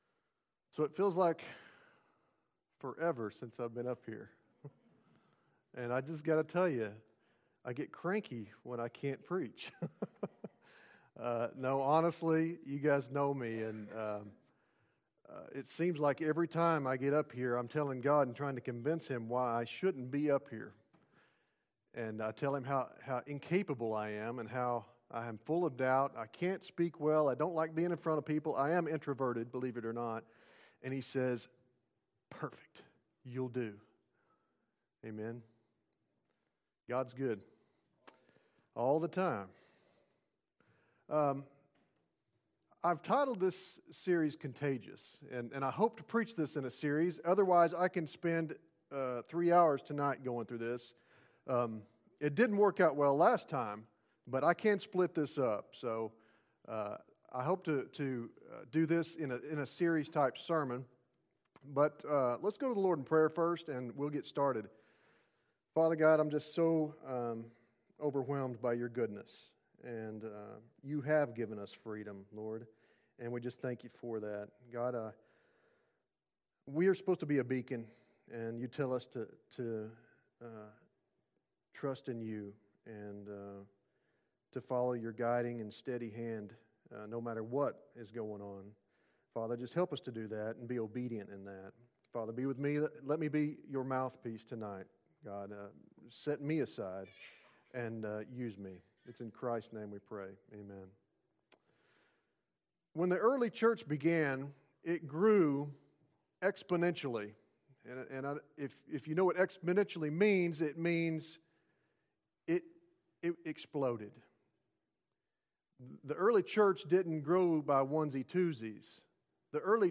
OBC-Audio-5-24-20-PM-Preaching.mp3